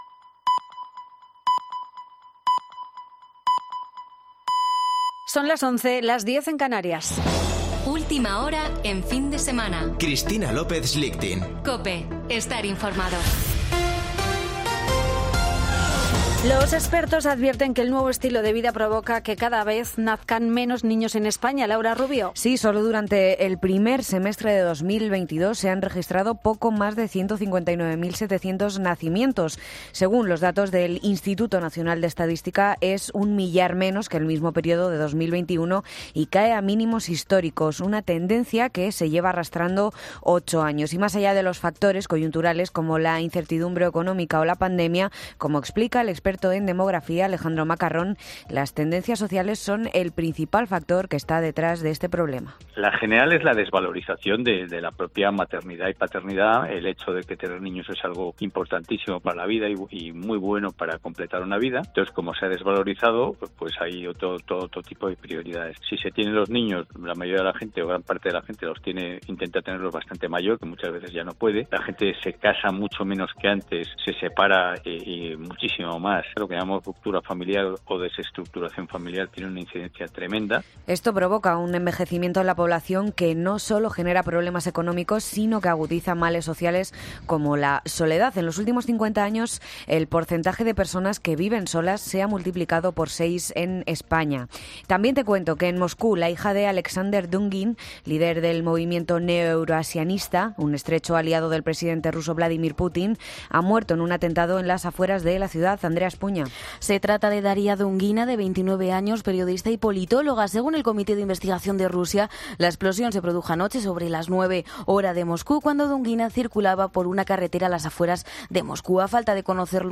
Boletín de noticias de COPE del 21 de agosto de 2022 a las 11.00 horas